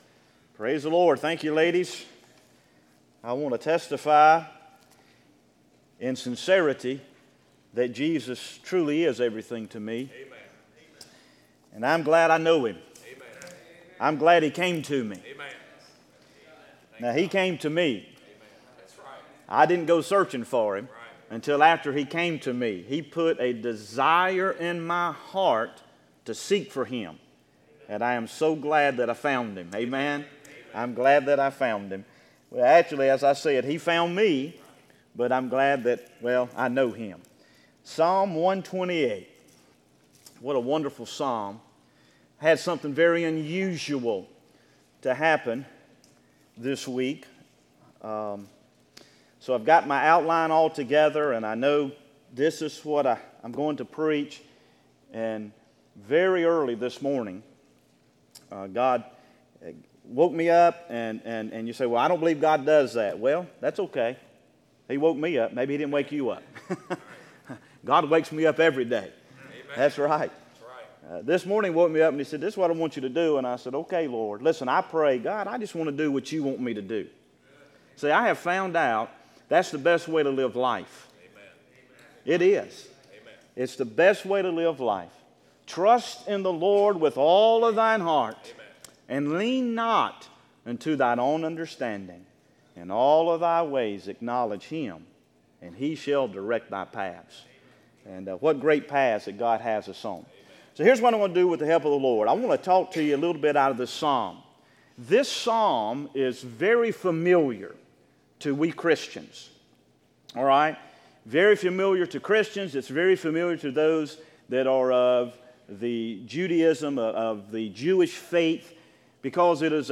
Sermons Archive • Page 46 of 166 • Fellowship Baptist Church - Madison, Virginia